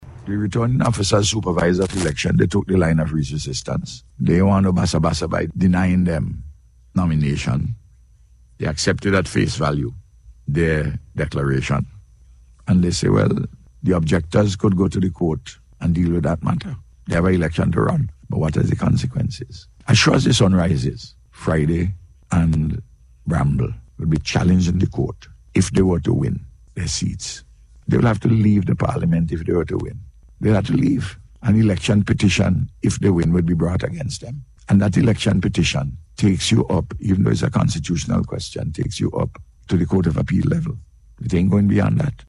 This was among several issues raised by Prime Minister Dr. Ralph Gonsalves on NBC Radio yesterday.